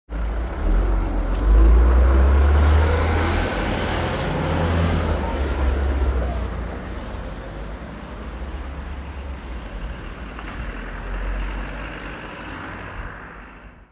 دانلود صدای اتوبوس 2 از ساعد نیوز با لینک مستقیم و کیفیت بالا
جلوه های صوتی